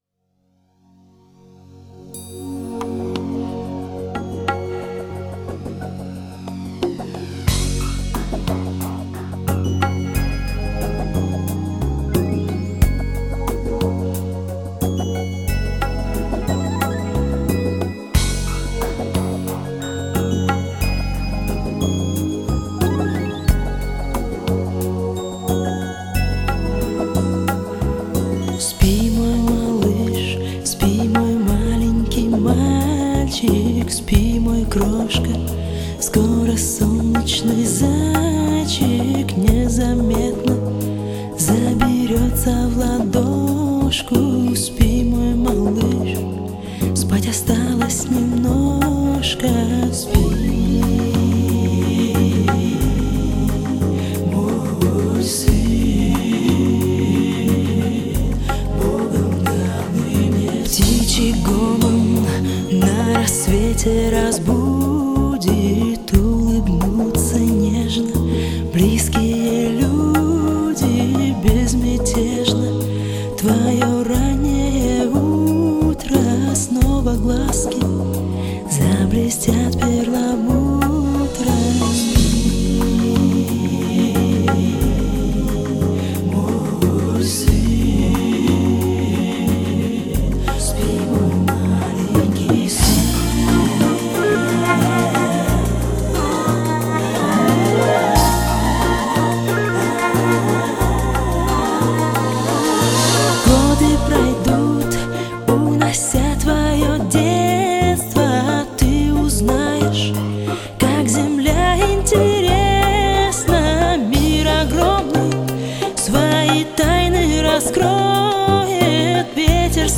Местная религиозная организация Церковь Христиан Адвентистов Седьмого Дня г.Тюмени
Проповедь о покаянии